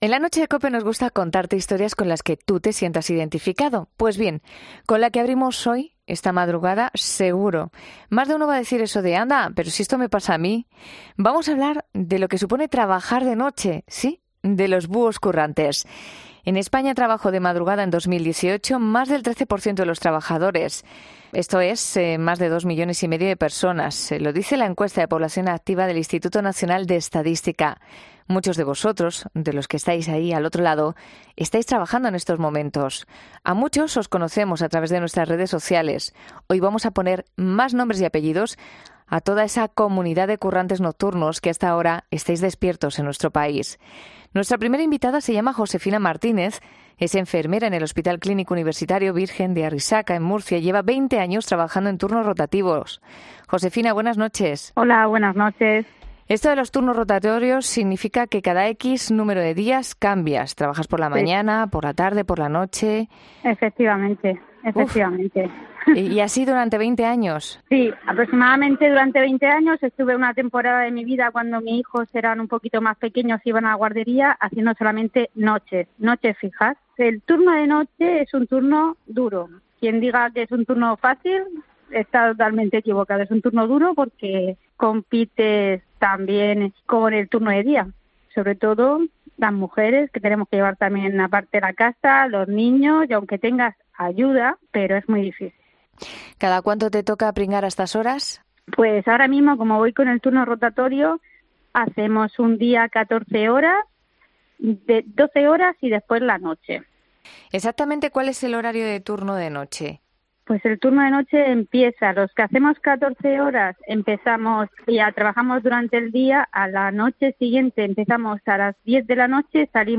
Una enfermera, una recepcionista de hotel y un médico de Urgencias, en 'La Noche'